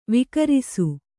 ♪ vikarisu